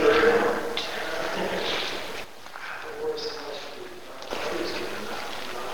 K Cabinets, Business In Columbus, Georgia
At about two seconds, you can hear the work “Ask” being said.